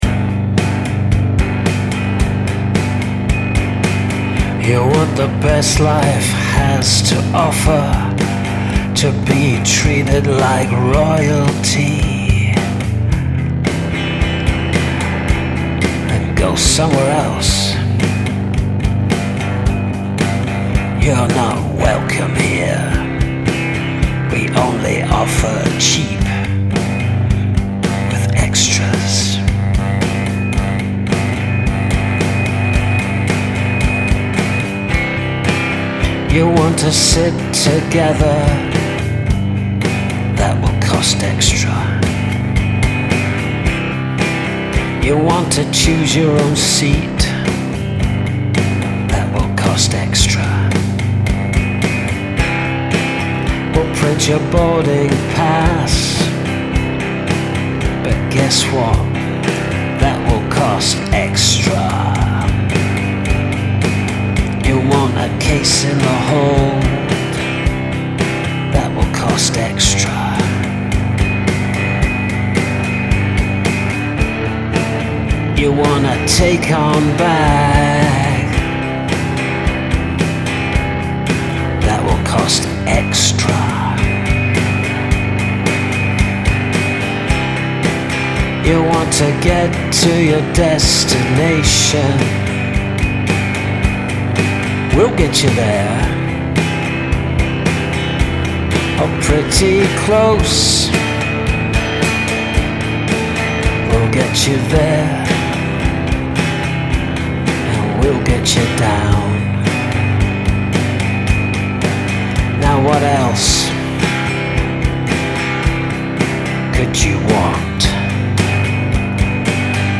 Skirmish: Unlikely advertising jing...
Couldn't place the band you were channeling exactly, but it certainly had that late 90s early noughties "indy" feel to it.